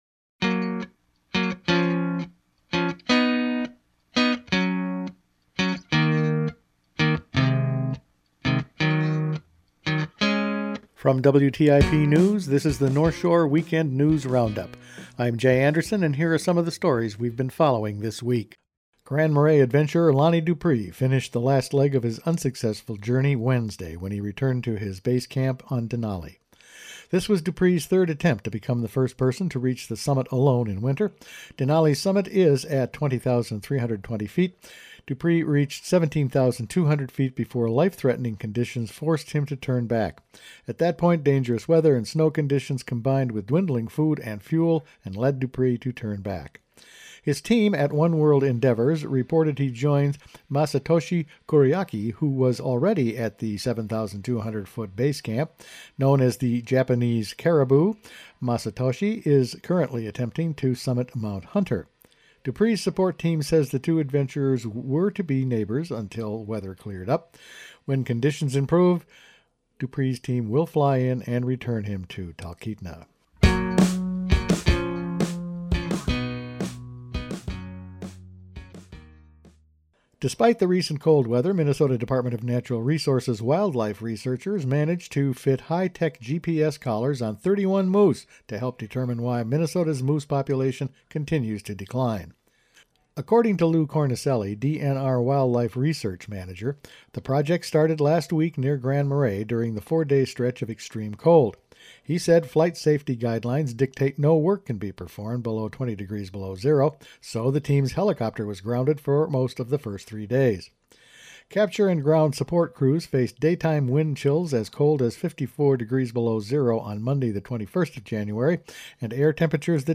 Each weekend WTIP news produces a round up of the news stories they’ve been following this week. Lonnie Dupre is forced to abandon his assault on Denali, The DNR begins GPS collaring moose while one county resident mounts a petition to stop the fall hunt.